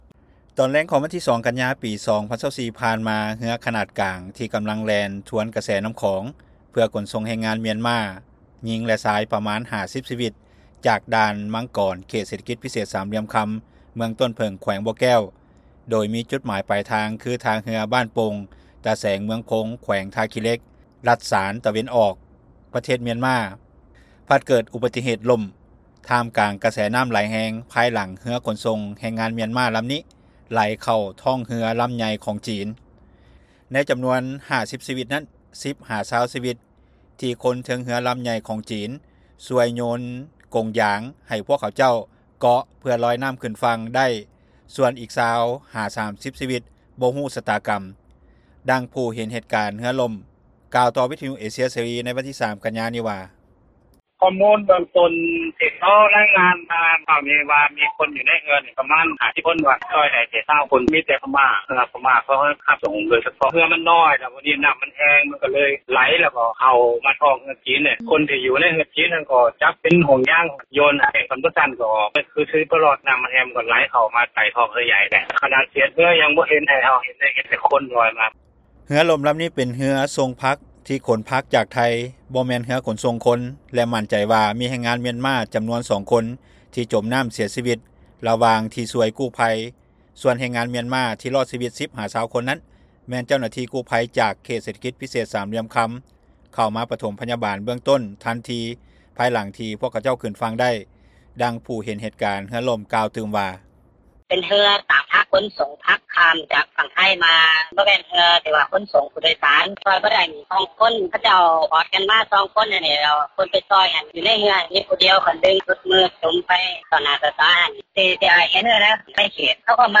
ດັ່ງຜູ້ເຫັນເຫດການເຮືອຫຼົ້ມ ກ່າວຕໍ່ວິທຍຸເອເຊັຽເສຣີ ໃນວັນທີ 03 ກັນຍາ ນີ້ວ່າ:
ດັ່ງເຈົ້າໜ້າທີ່ໄທກ່າວວ່າ.